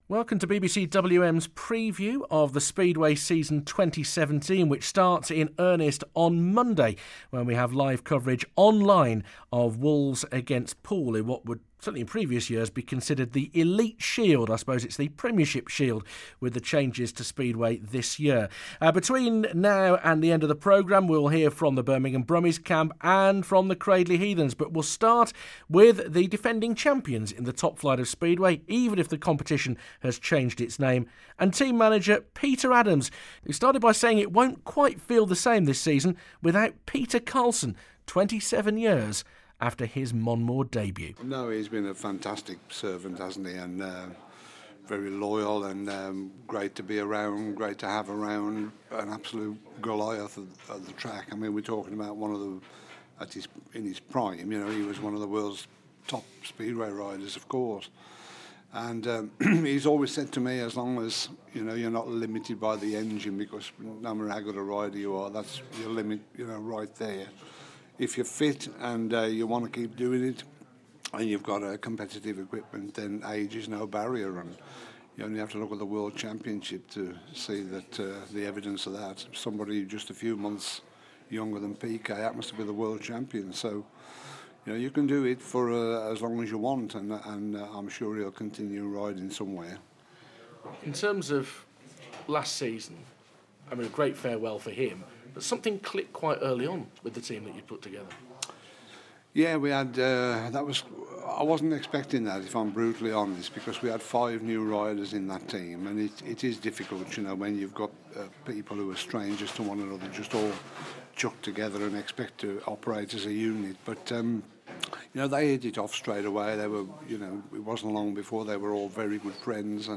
BBC WM looks ahead to the speedway season for Wolves, the Birmingham Brummies and the Cradley Heathens including interviews with Freddie Lindgren